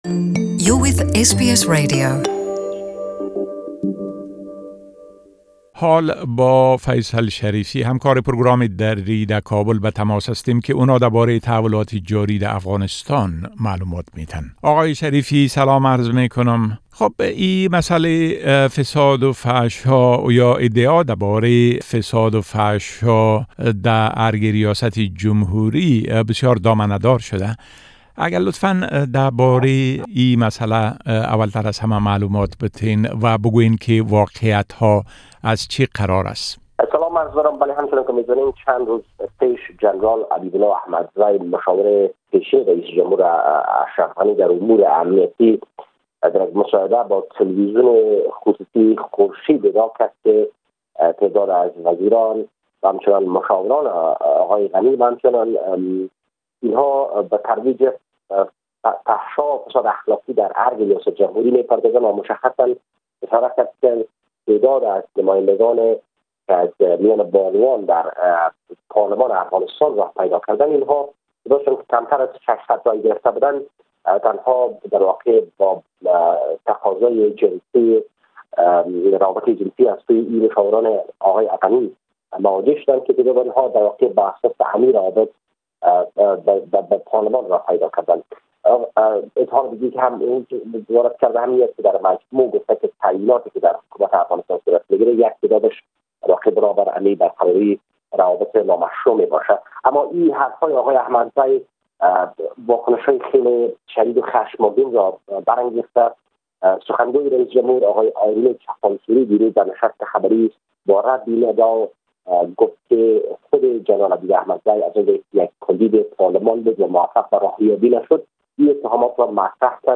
A report from our correspondent in Afghanistan